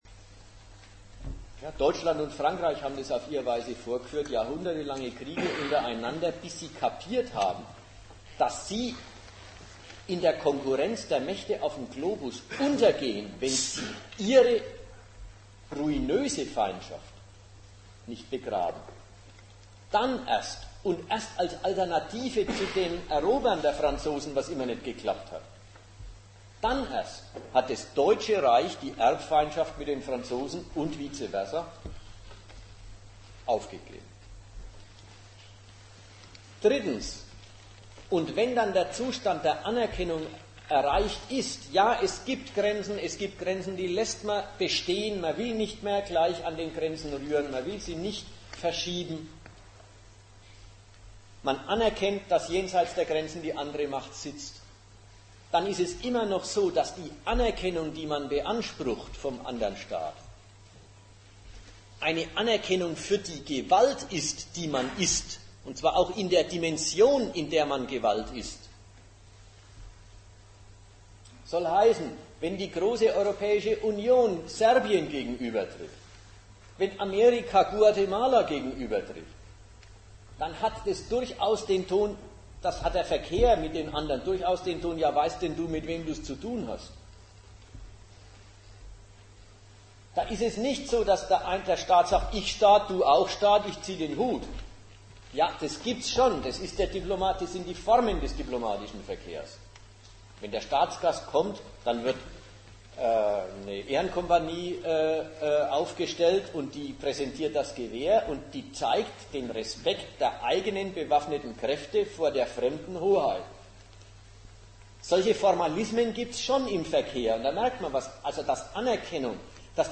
Ort Nürnberg
Dozent Gastreferenten der Zeitschrift GegenStandpunkt